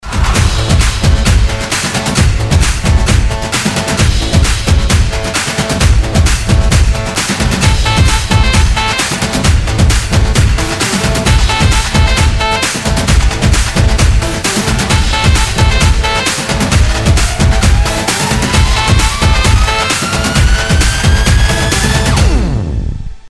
• Качество: 320, Stereo
dance
без слов
Electronica
динамичные
болливуд
Indian